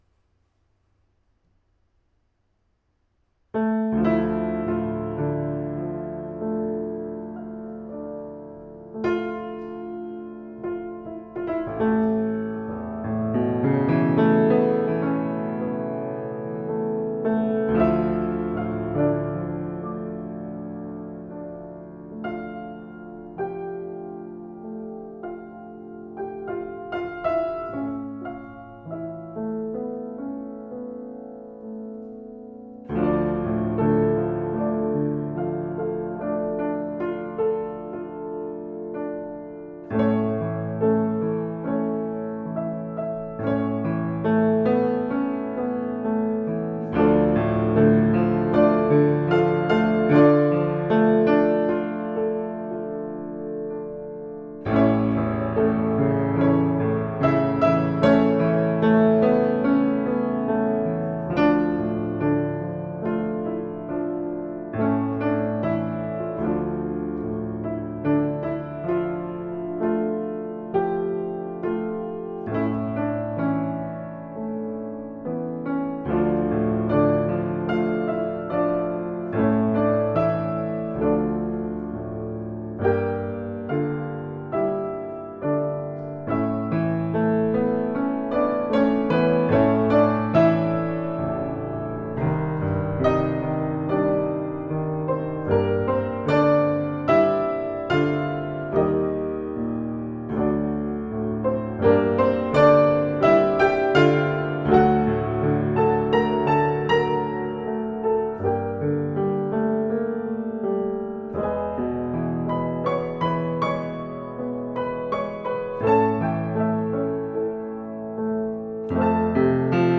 "Waterfalling" is here entitled which was recorded in 2015 from a "control" room of sorts where there was not an effort to make any acoustical design.